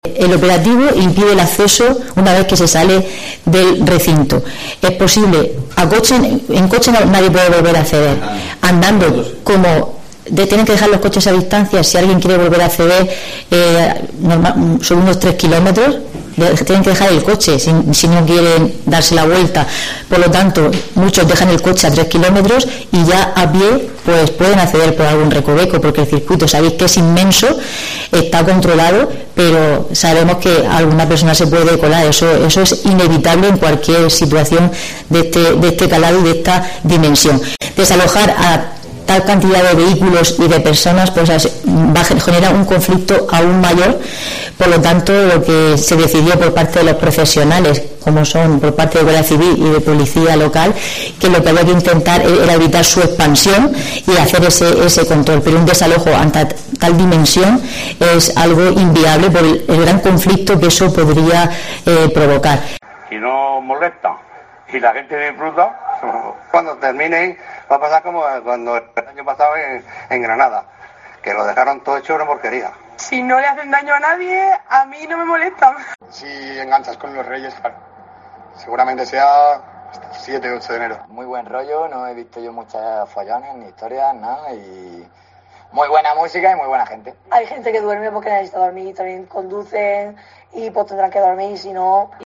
La 'rave' de Fuente Álamo, que lleva activa desde Nochevieja con unas cinco mil personas, no será desalojada por las fuerzas del orden público por seguridad, según ha confirmado este miércoles en rueda de prensa la delegada del Gobierno en la Región, Mariola Guevara.